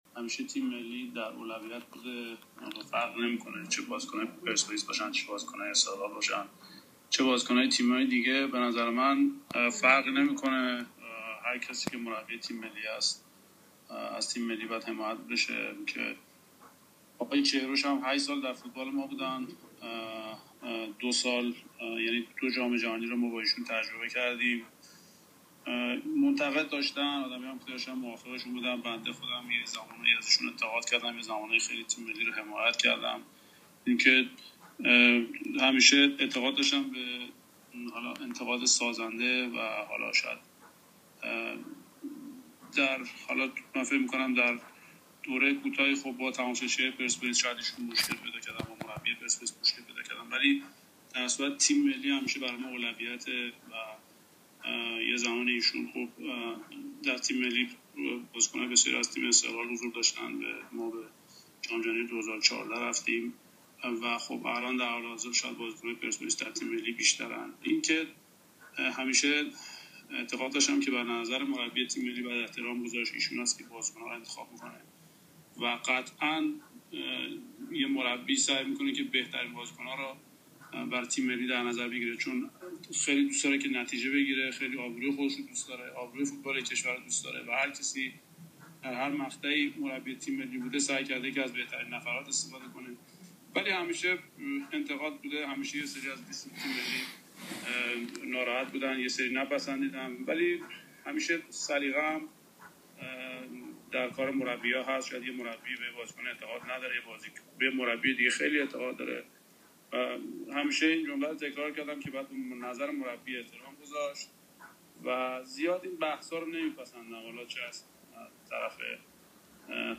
مهدی مهدوی کیا پیشکسوت فوتبال ایران در کلاب هاوس درباره انتقادات از لیست تیم ملی و حضور پرتعداد بازیکنان پرسپولیس در این لیست توضیحاتی داد.